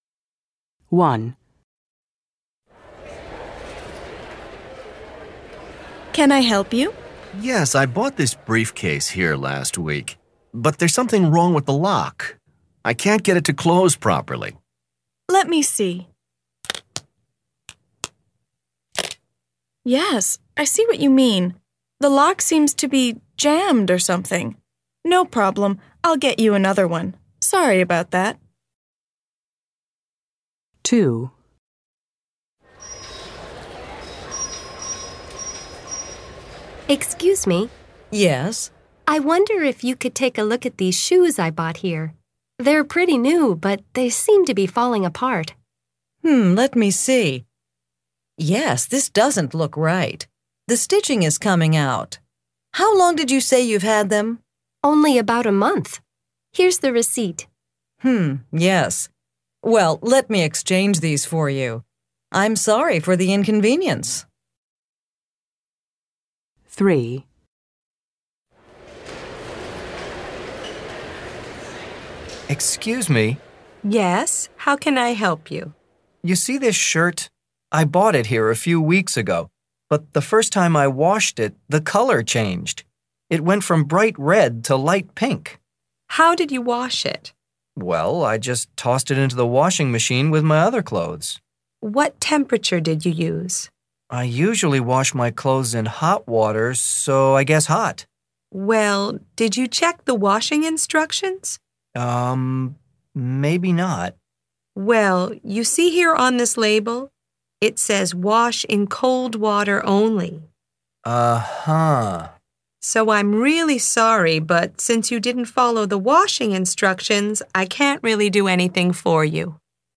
Listen to three customers return an item they purchased: